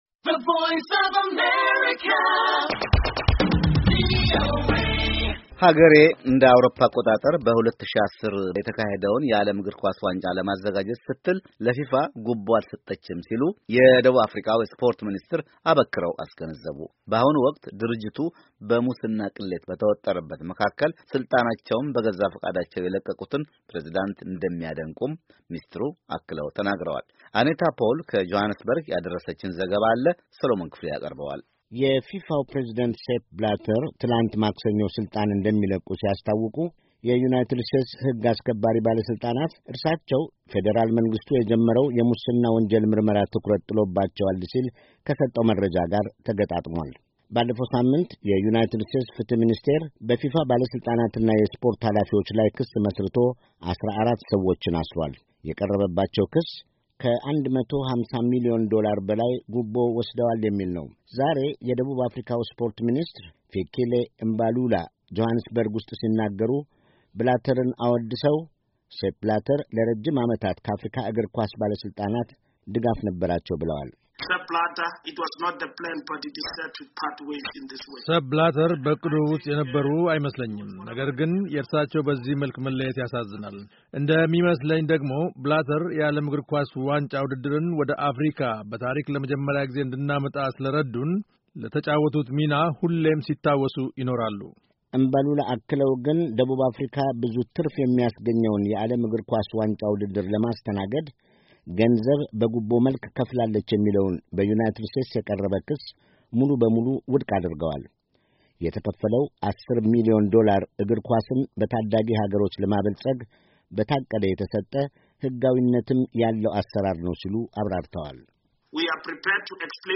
ጆሃንስበርግ፤ ዋሺንግተን ዲሲ፣ አዲስ አበባ —